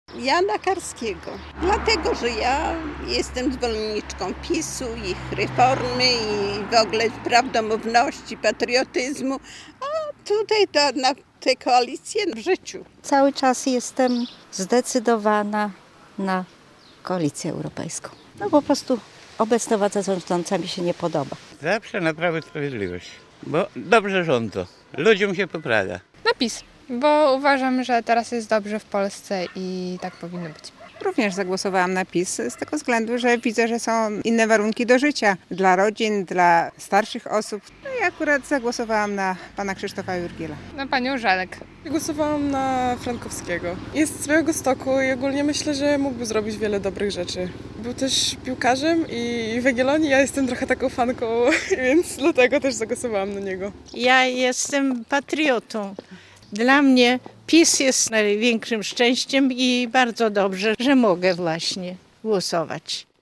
Na kogo głosowali mieszkańcy Supraśla? - relacja